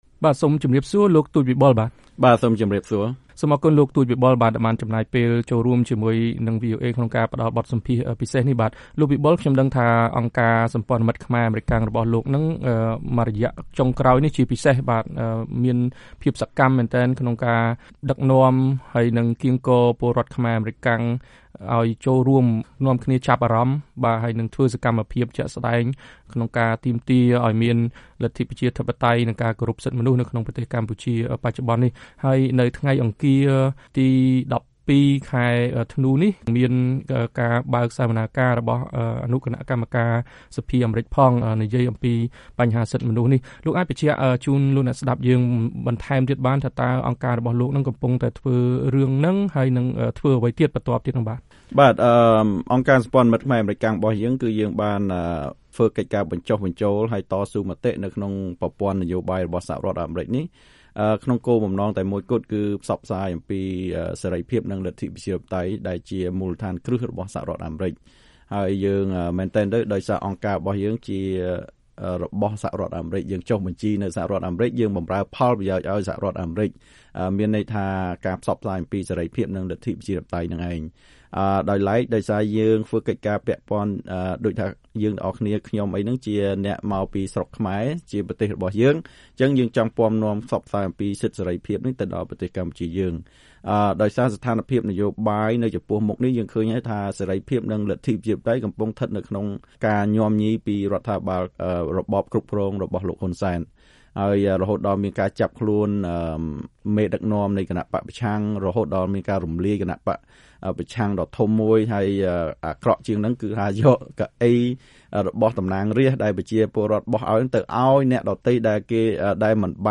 បទសម្ភាសន៍ VOA៖ អង្គការសម្ព័ន្ធមិត្តខ្មែរ-អាមេរិកាំងថា ពលរដ្ឋខ្មែរត្រូវតែហ៊ានលះបង់ប្រយោជន៍ខ្លីដើម្បីផលប្រយោជន៍យូរអង្វែង